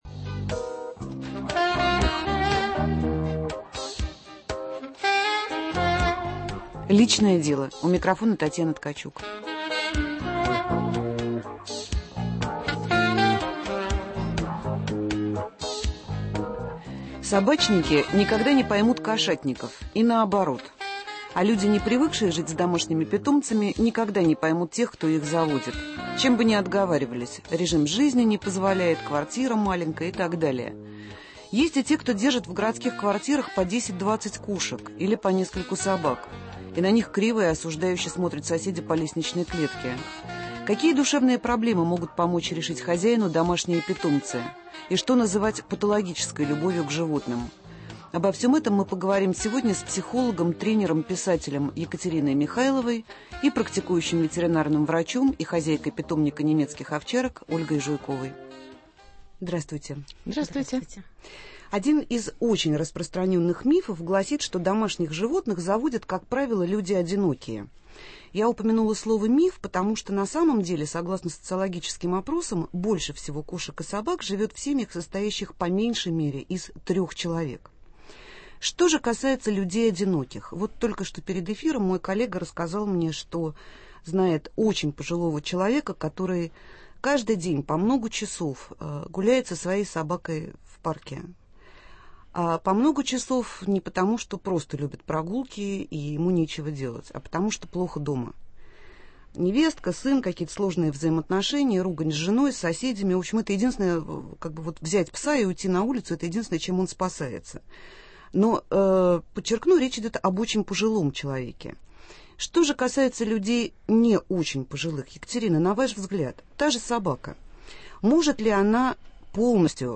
В прямом эфире мы поговорим о том, зачем и почему люди заводят домашних животных - кошек, собак, рыбок, птиц и так далее. Правда ли, что питомцы лечат от одиночества? Может ли любовь к домашним животным стать патологической?